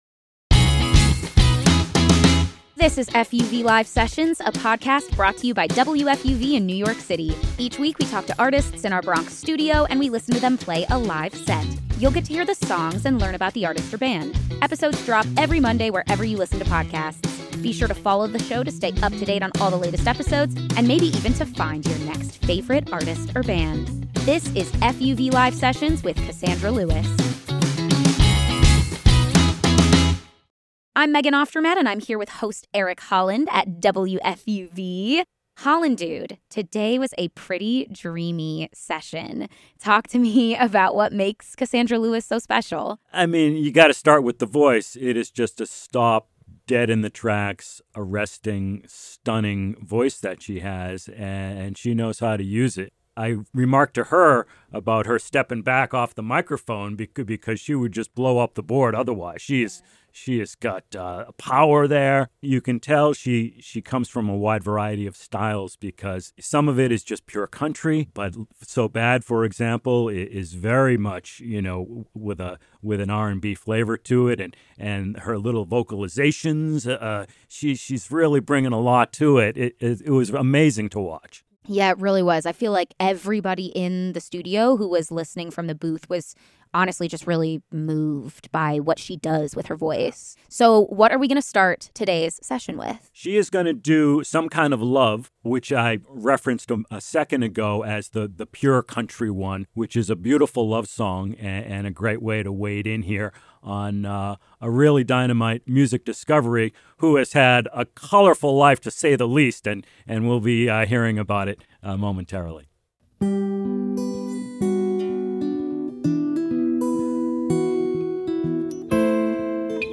Vocal powerhouse